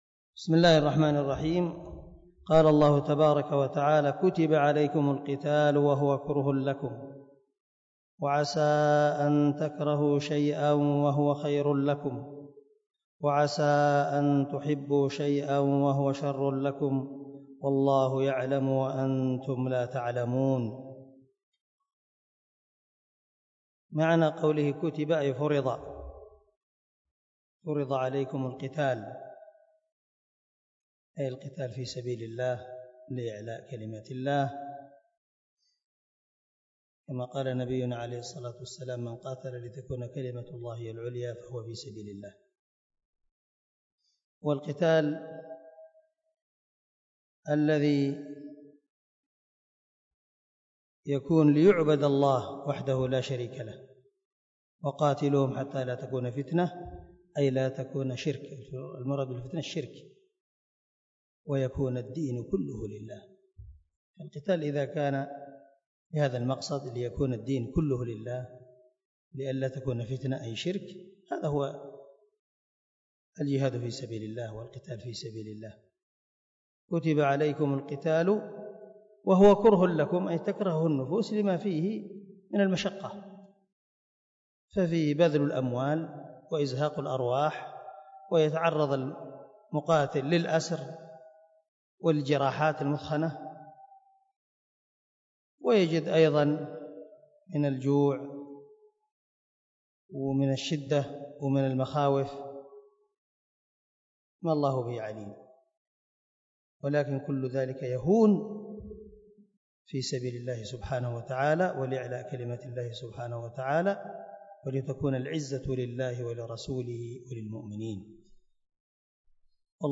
105الدرس 95 تفسير آية ( 216 - 217 ) من سورة البقرة من تفسير القران الكريم مع قراءة لتفسير السعدي